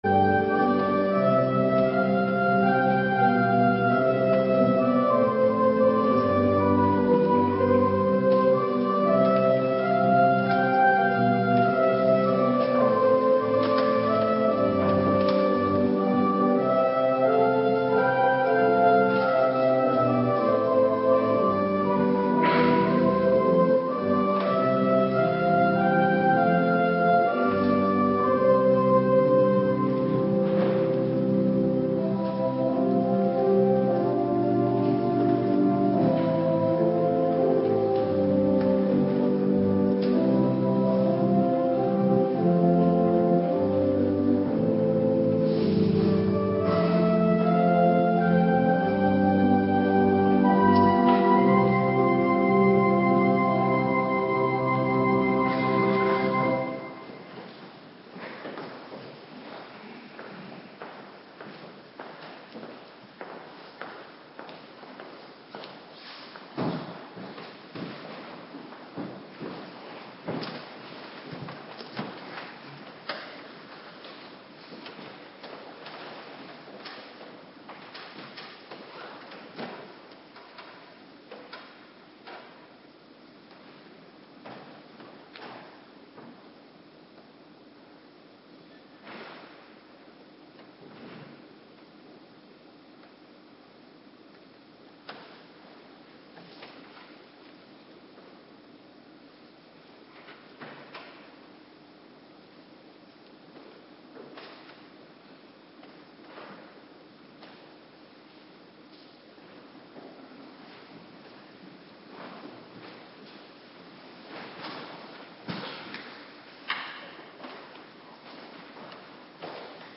Avonddienst - Cluster 2
Locatie: Hervormde Gemeente Waarder